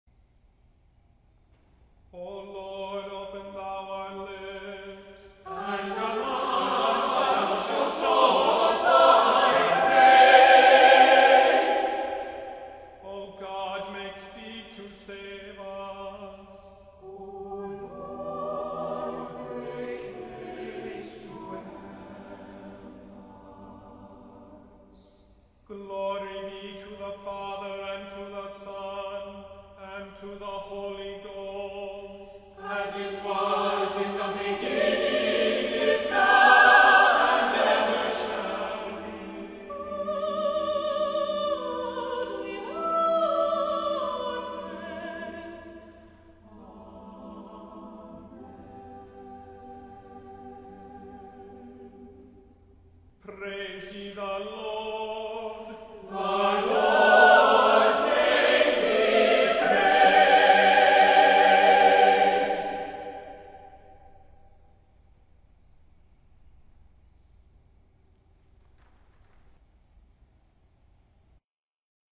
• Music Type: Choral
• Voicing: SATB with divisi
• Accompaniment: a cappella
• Liturgical Celebrations: Preces and Responses, Evensong